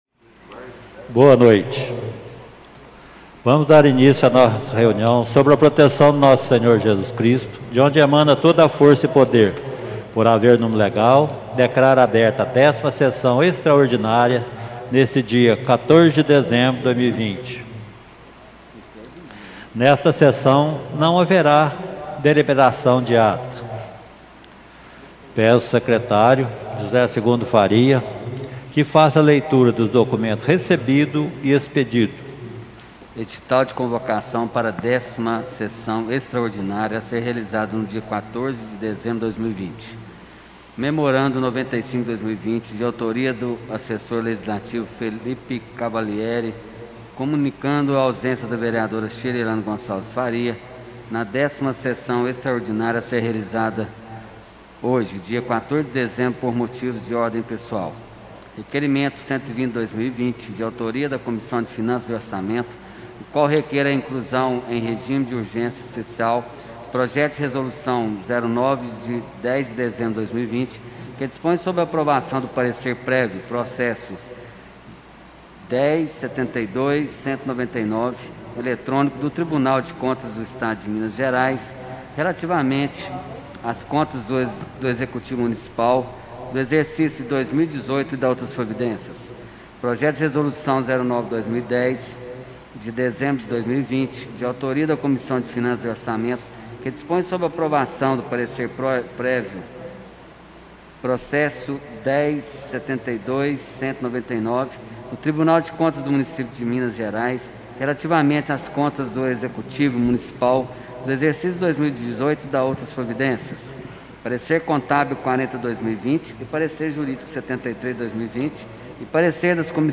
Tipo de Sessão: Extraordinária